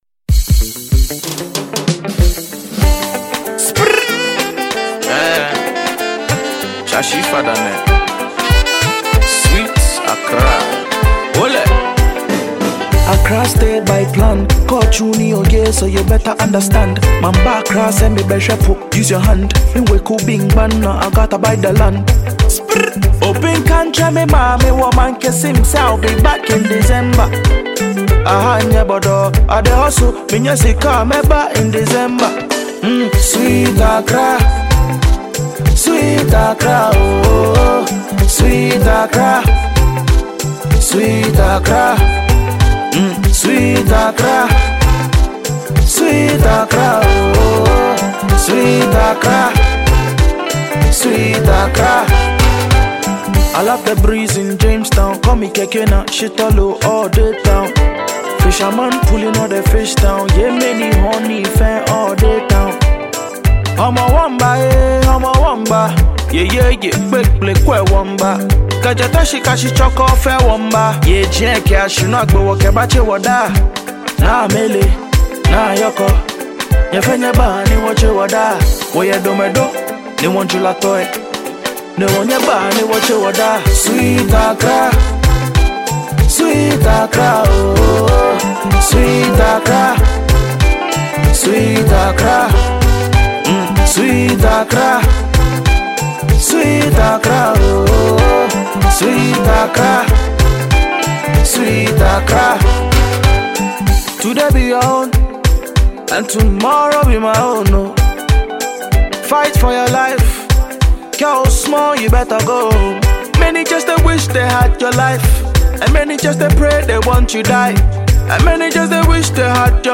GHANA MUSIC
high-life song